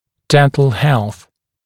[‘dent(ə)l helθ][‘дэнт(э)л хэлс]здоровье зубов; стоматологический статус; стоматологическое здоровье